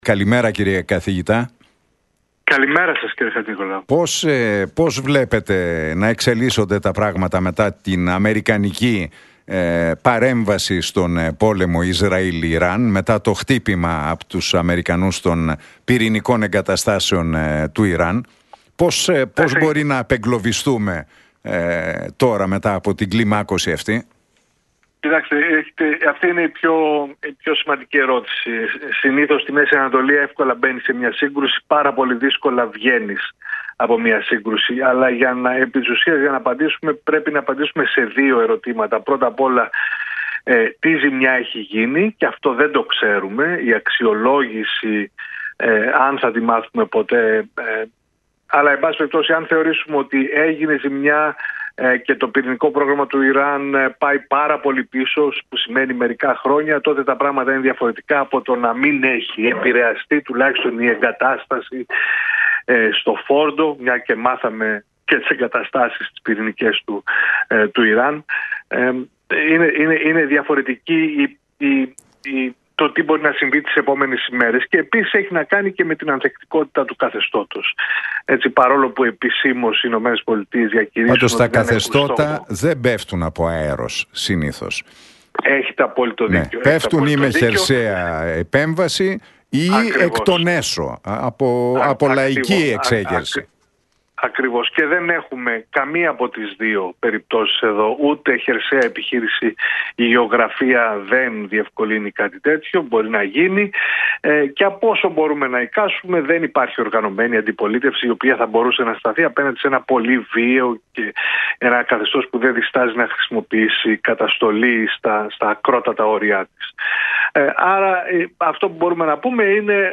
από την συχνότητα του Realfm 97,8